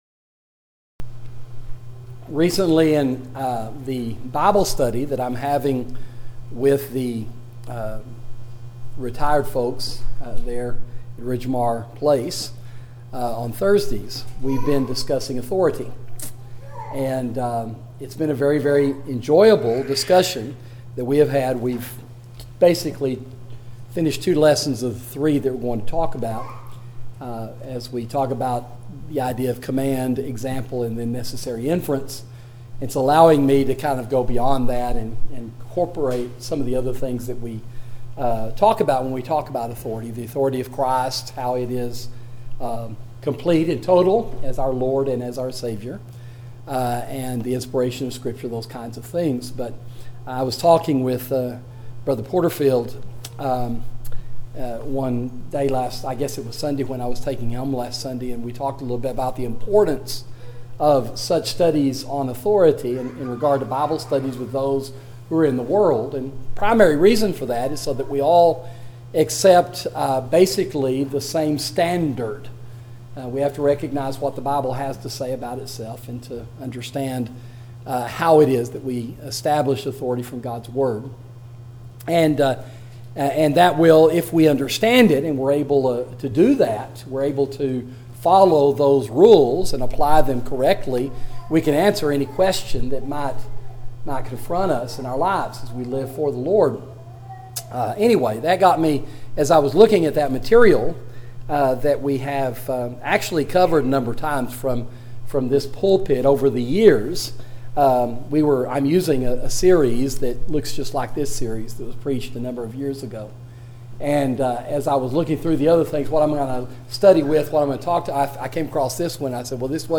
Sermon: The Divine Hermeneutic